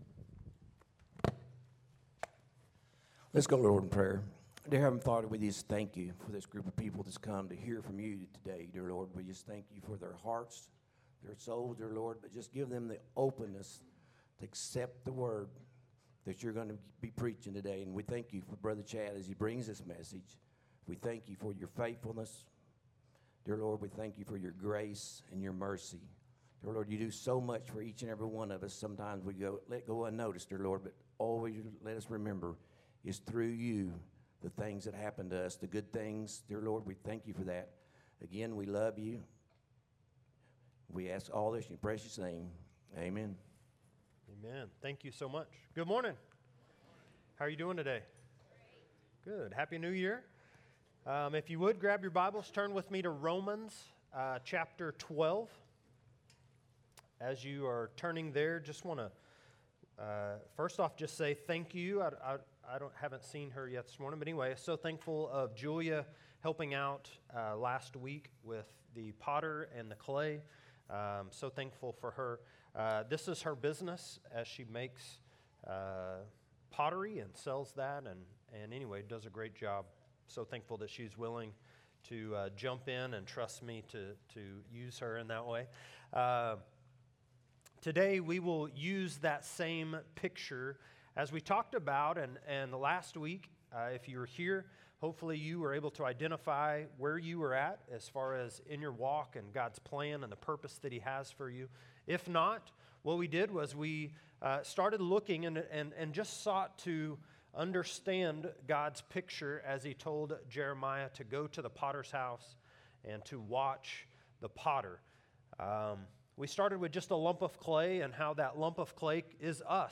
A message from the series "What is your Purpose?."